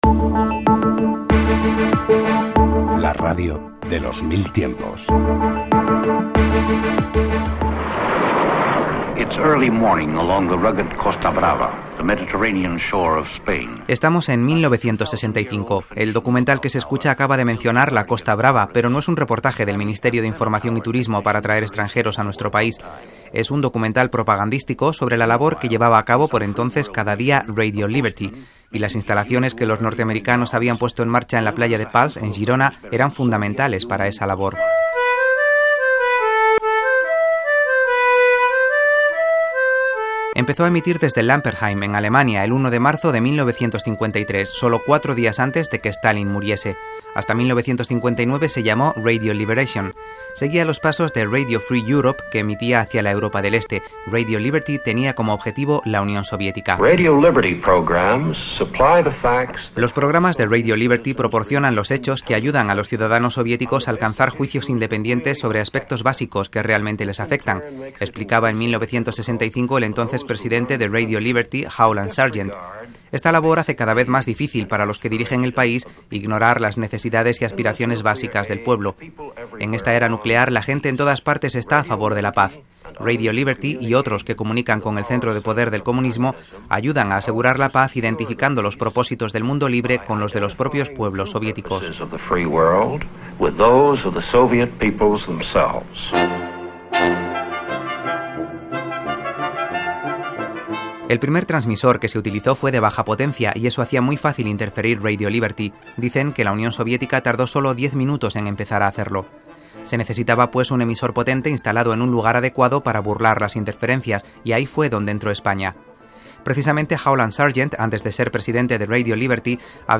Careta del programa i espai dedicat a Radio Liberty i la seva planta emissora a la platja de Pals
Divulgació